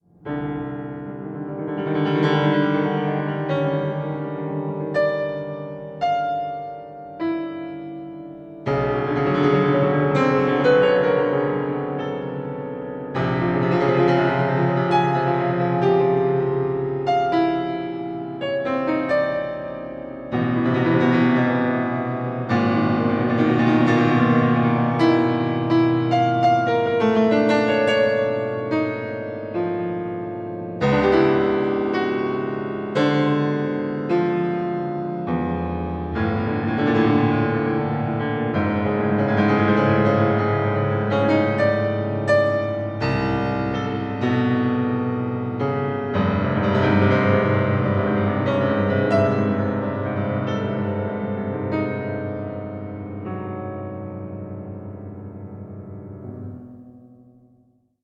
24 bit stereo